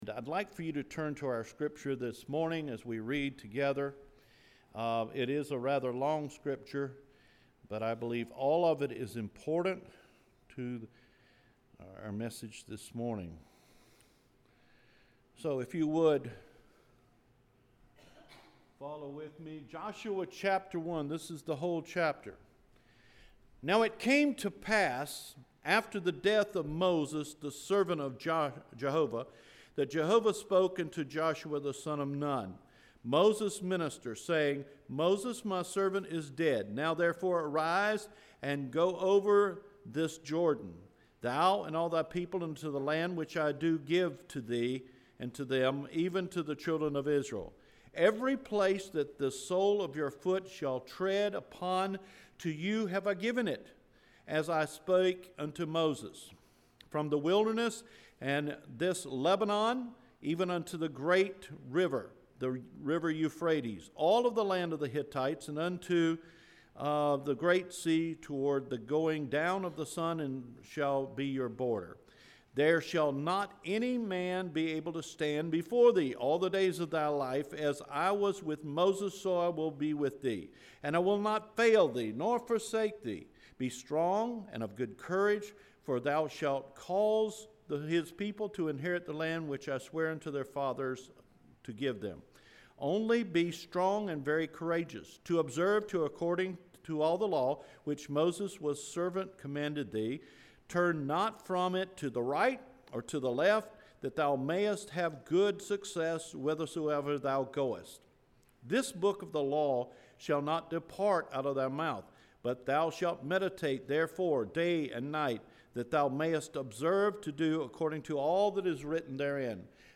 June 24 Sermon – Cedar Fork Baptist Church